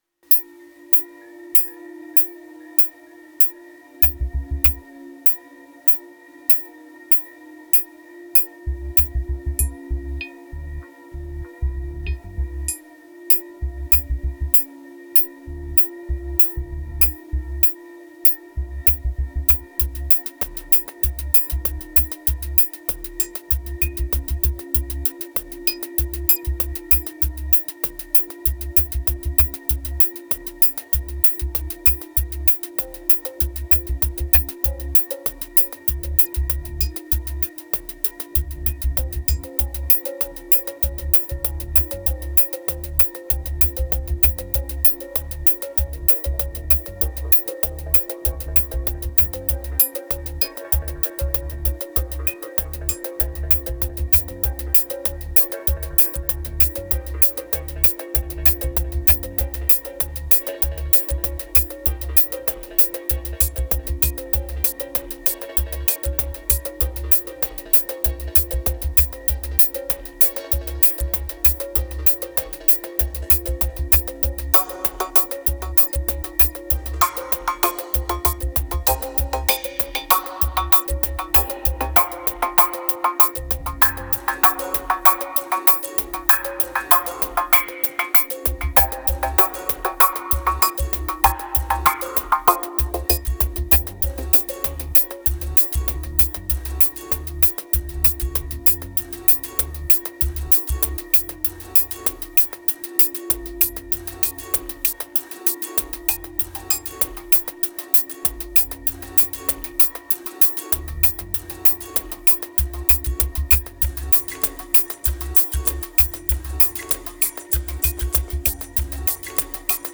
2958📈 - 85%🤔 - 97BPM🔊 - 2017-02-12📅 - 746🌟